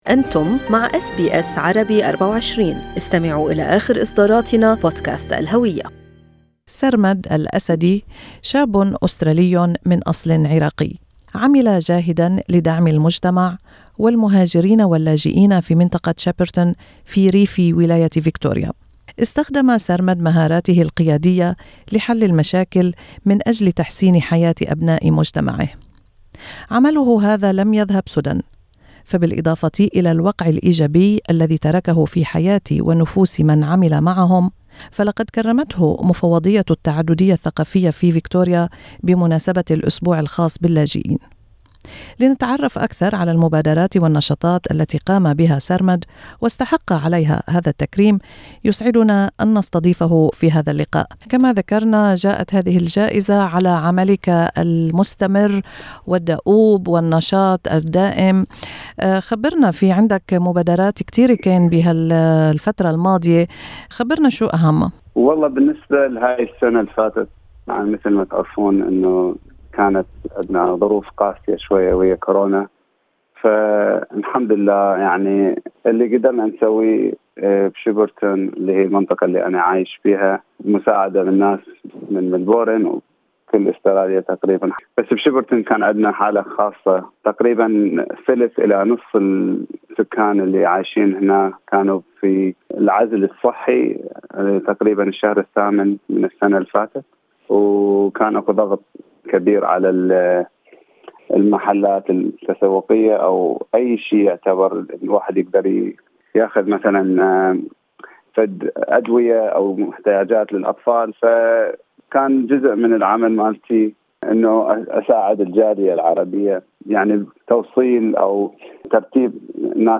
استمعوا إلى اللقاء كاملا في المدونة الصوتية في أعلى الصفحة.